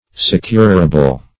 Securable \Se*cur"a*ble\, a.